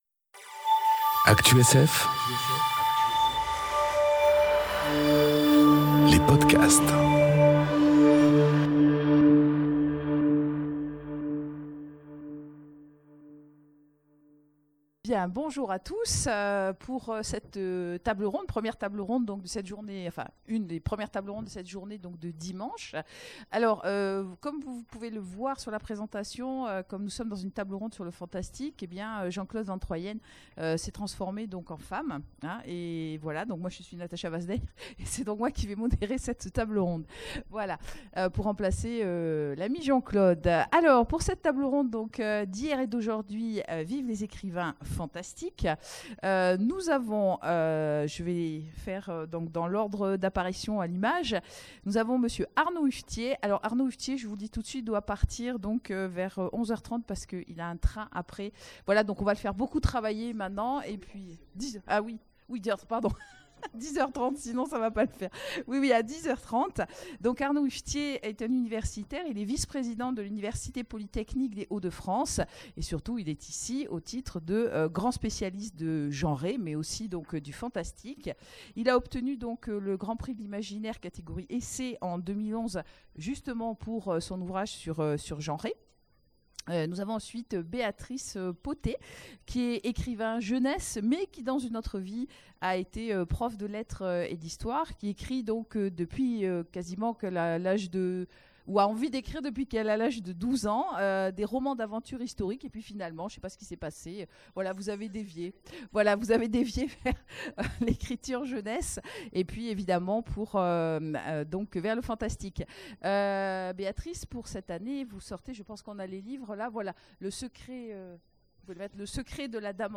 Imaginales 2018 : Conférence D'hier et d'aujourd'hui... Vive les écrivains fantastiques
Imaginales 2018 : Conférence D'hier et d'aujourd'hui...